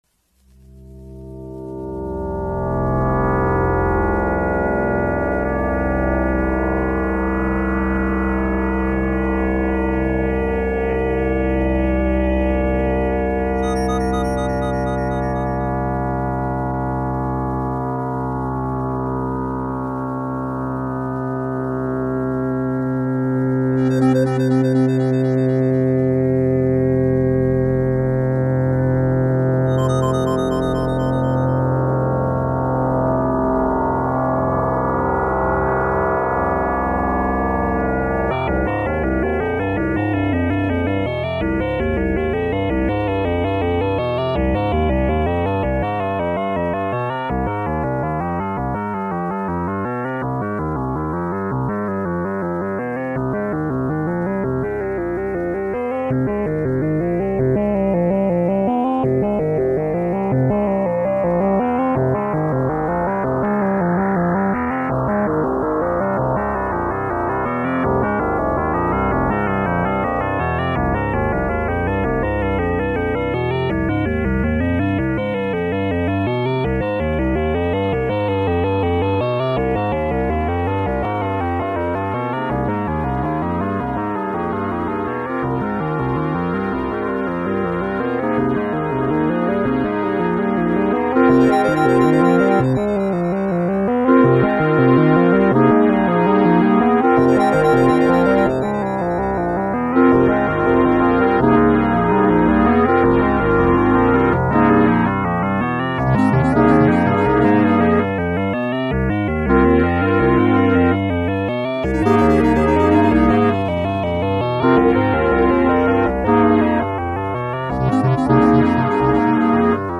Here are some recordings of IMS music. The sound quality varies greatly as they are all from old cassette tapes, some of which were recorded with the input level too high...